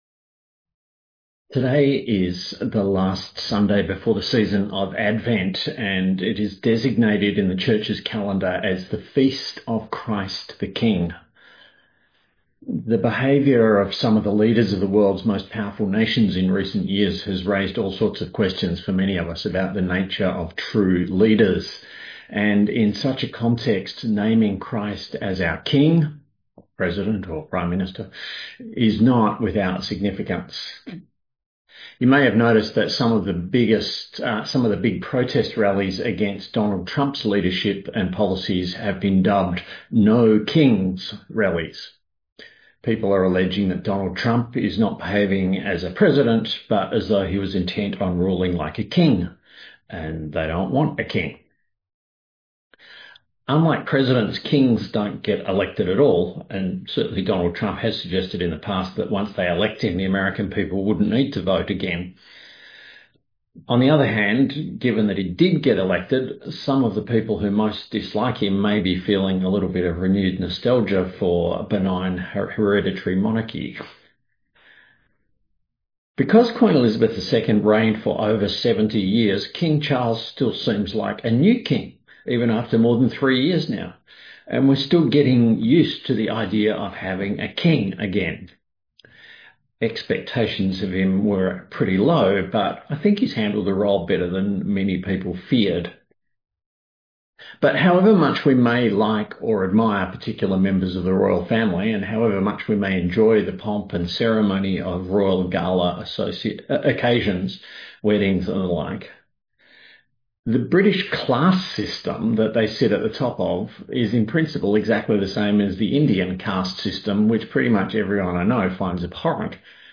A sermon on Jeremiah 23: 1-6 & Luke 23: 33-43